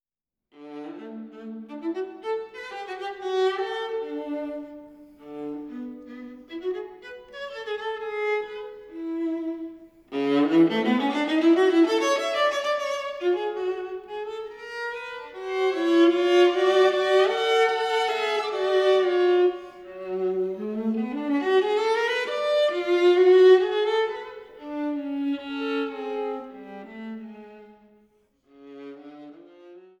im Studio der Musikhochschule aufgenommen